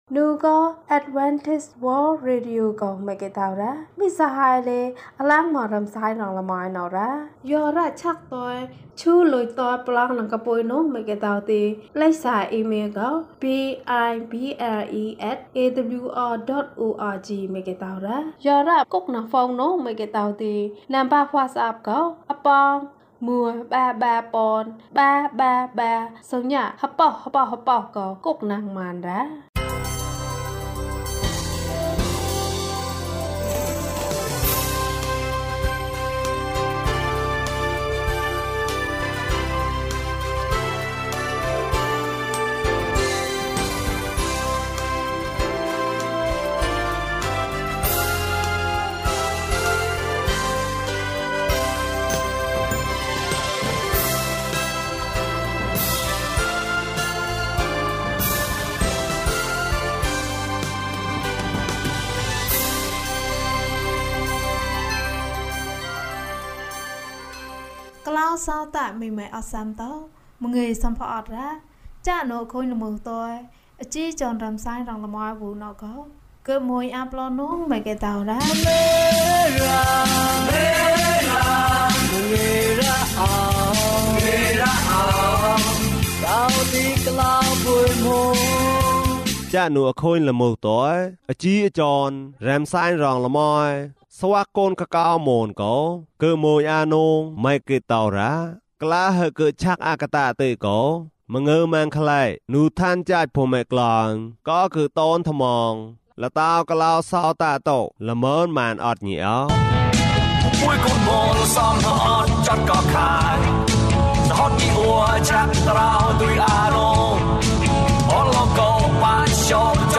ငါသူ့ကိုယုံတယ်။၀၁ ကျန်းမာခြင်းအကြောင်းအရာ။ ဓမ္မသီချင်း။ တရားဒေသနာ။